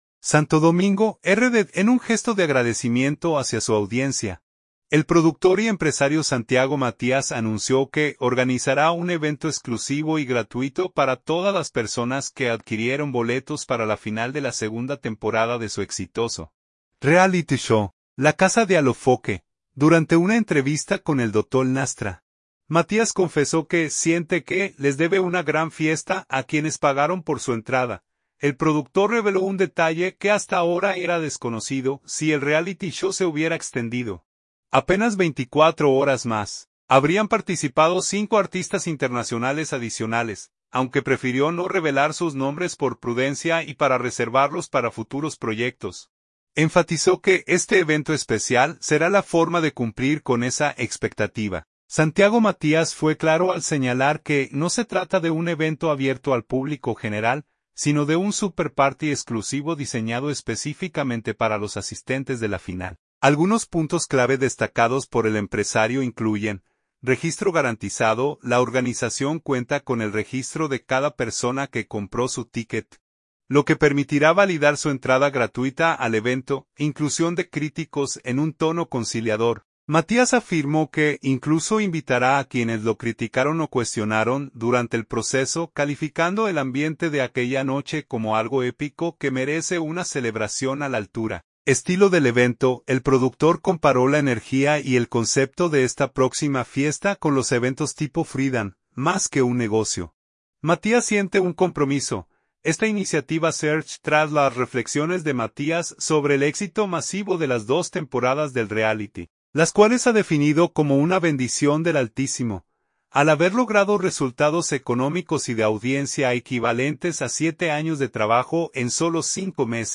Durante una entrevista con El Dotol Nastra, Matías confesó que siente que les debe una gran fiesta a quienes pagaron por su entrada.